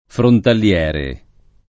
frontaliere [ frontal L$ re ]